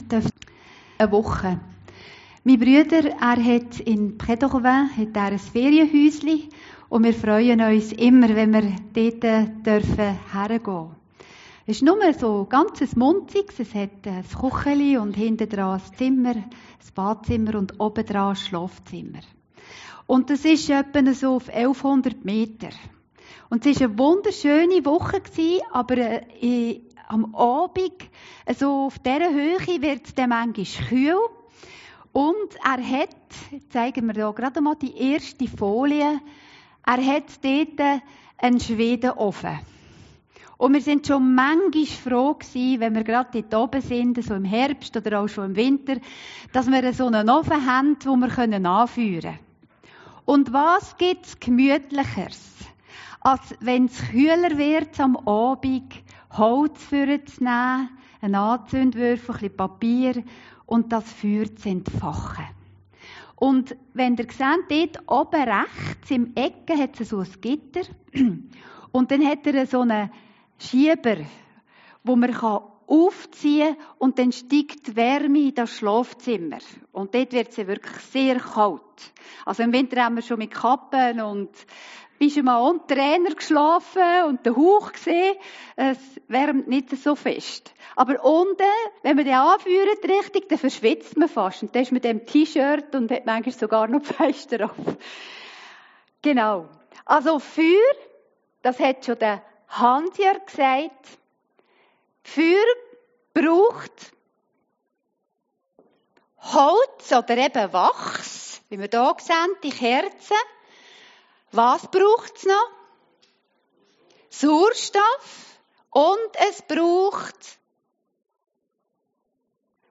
Predigten Heilsarmee Aargau Süd – FEUER UND FLAMME FÜR JESUS